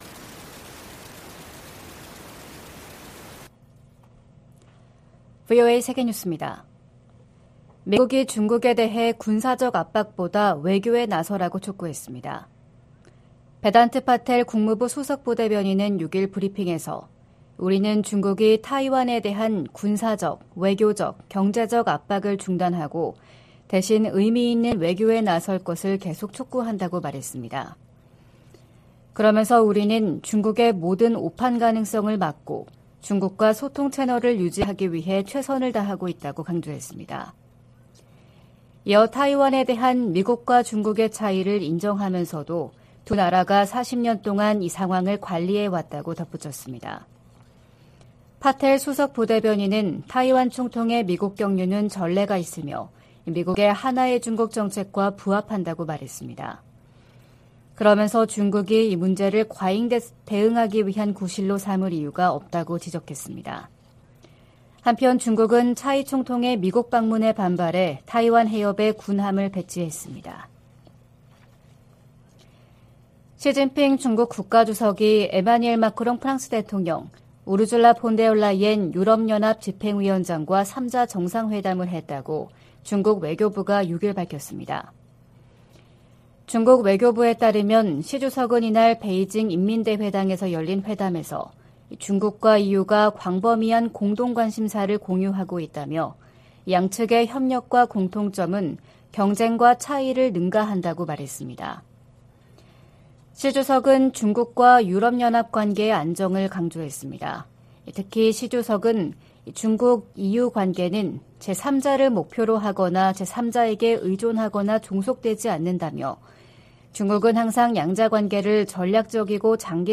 VOA 한국어 '출발 뉴스 쇼', 2023년 4월 8일 방송입니다.